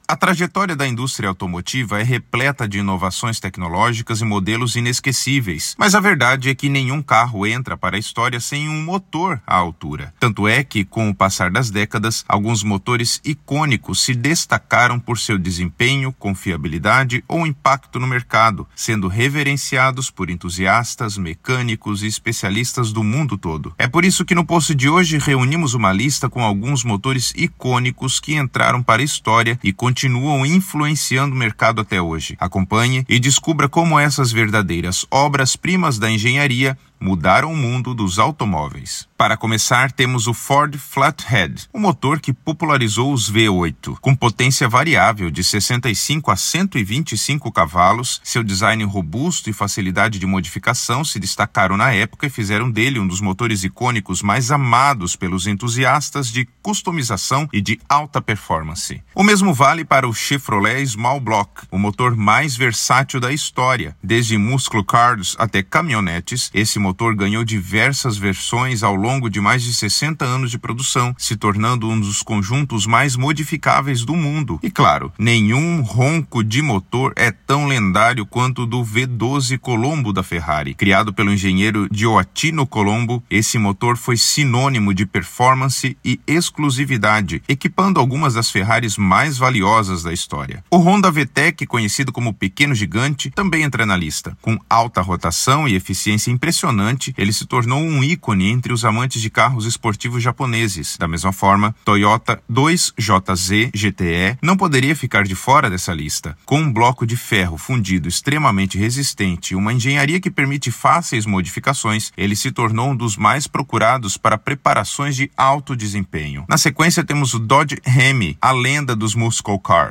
Narracao-02-motores-iconicos.mp3